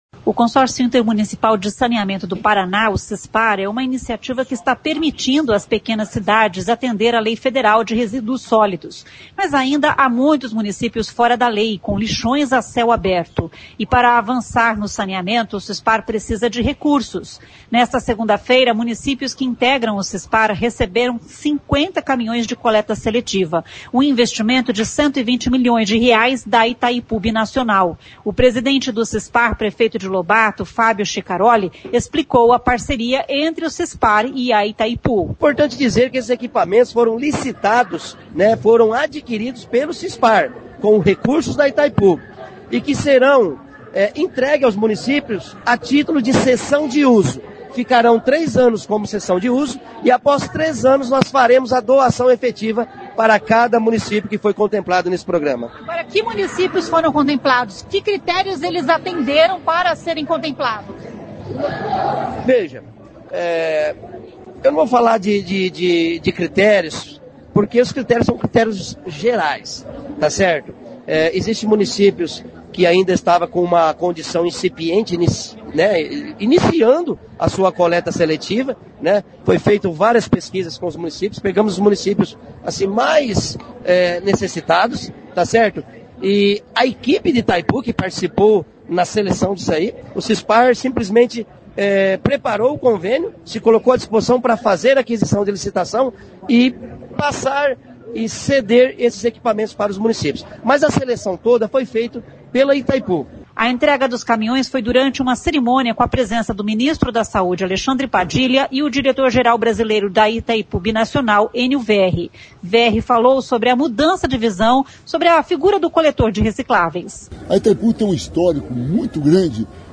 O presidente do Cispar, prefeito de Lobato, Fábio Chicaroli explicou a parceria entre Cispar e Itaipu.
Verri falou sobre a mudança de visão sobre a figura do coletor de recicláveis.[ouça o áudio]